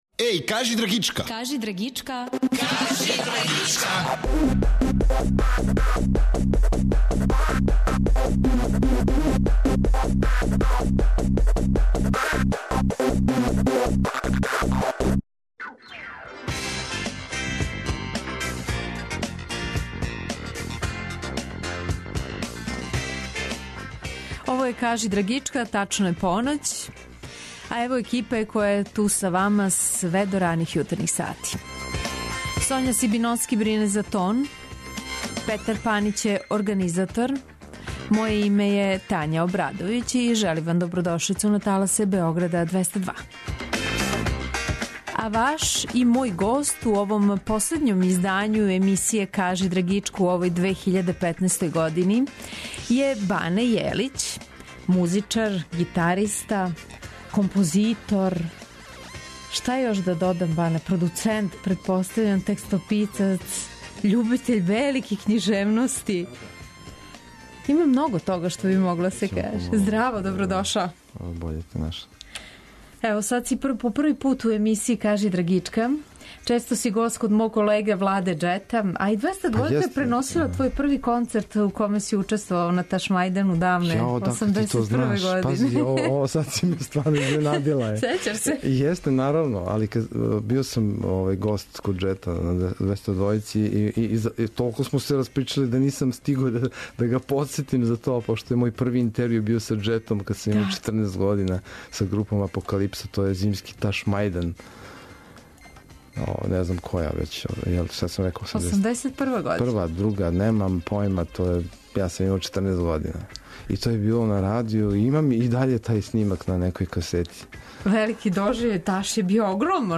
Свако вече, од поноћи на Двестадвојци у емисији Кажи драгичка гост изненађења!